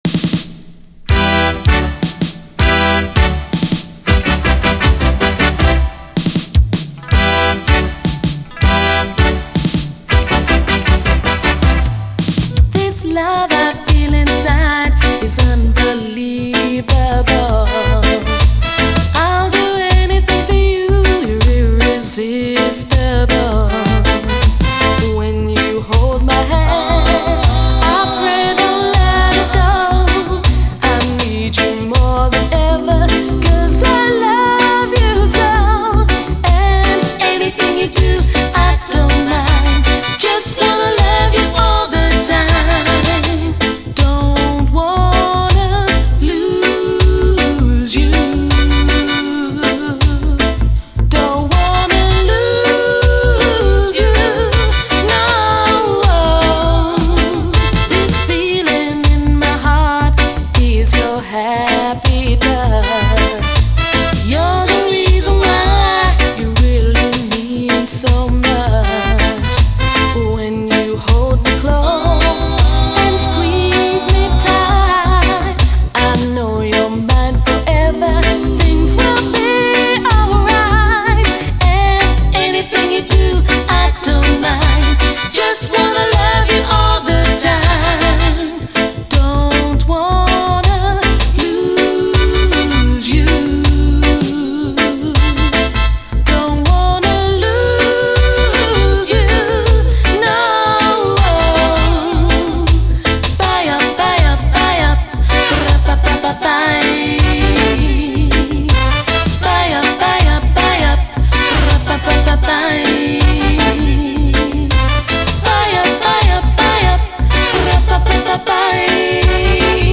REGGAE